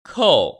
[kòu] 커우